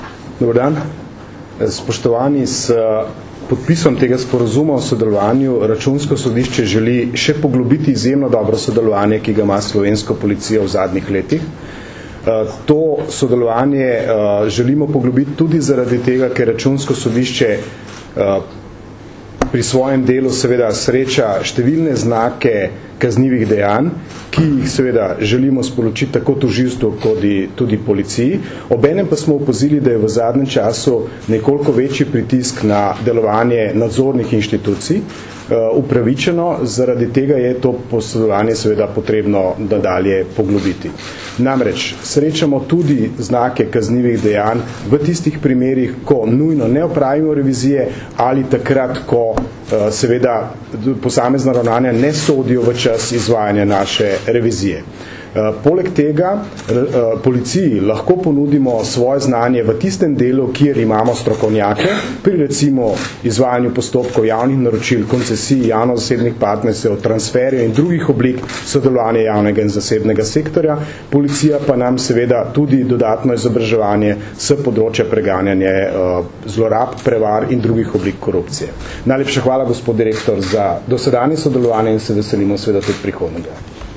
Zvočni posnetek izjave Tomaža Vesela (mp3)